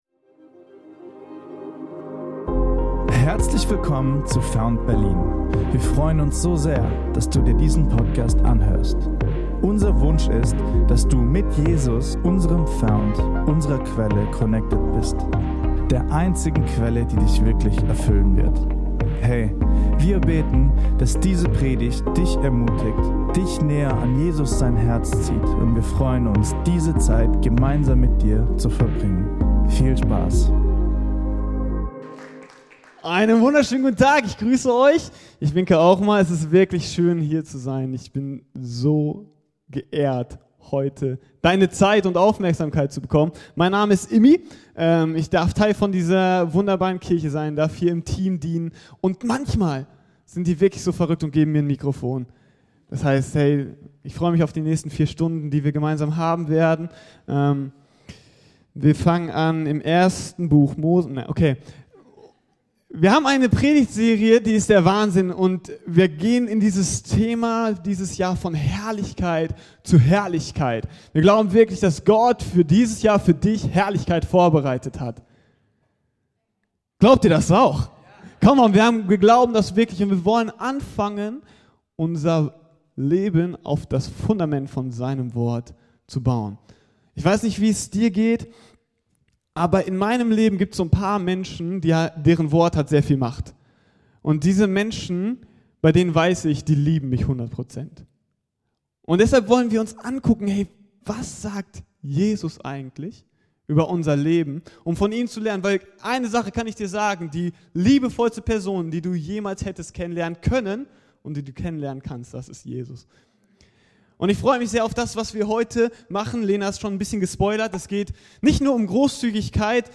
— Erfahre in dieser Predigt, was es aus göttlicher Perspektive bedeutet, in finanzieller Freiheit zu leben.